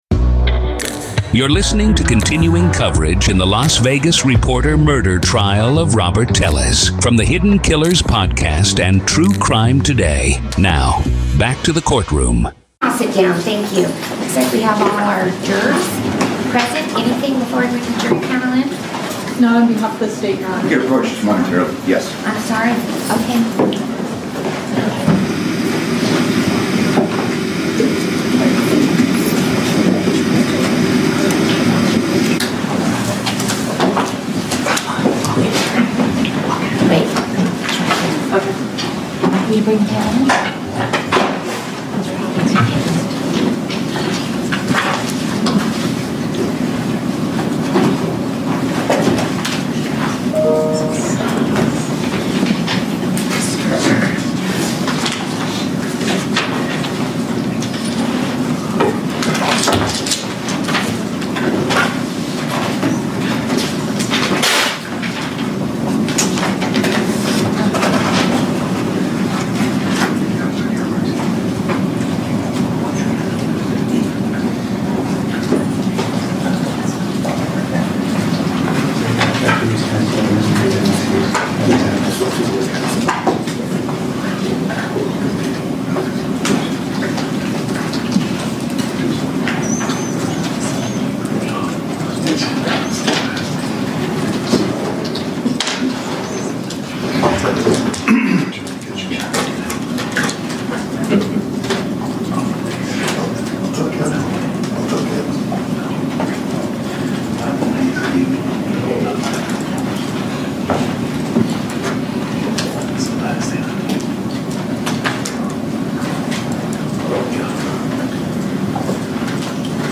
Court Audio-NV v. Robert Telles DAY 1 Part 1